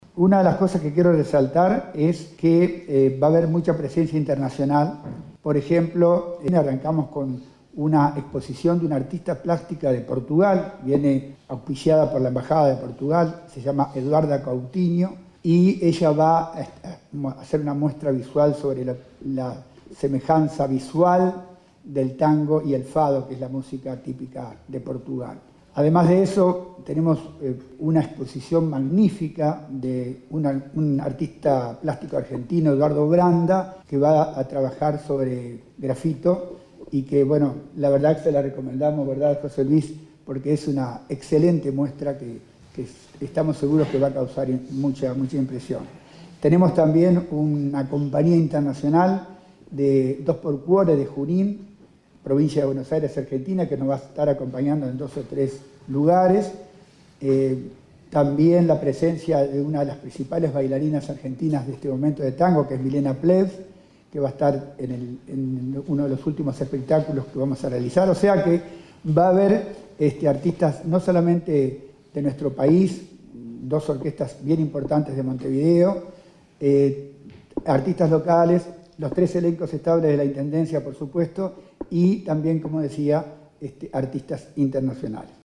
Habrá artistas locales y dos orquestas importantes de Montevideo, además de los elencos estables de la Intendencia, según indicó el director de cultura de la comuna. Eduardo Barale.
Informe